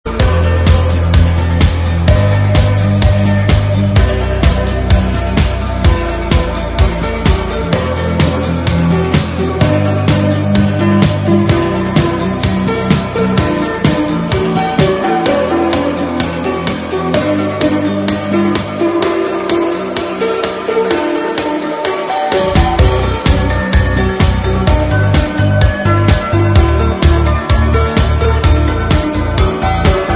Kristal Club, Bucharest, Romania (11-Nov-2004)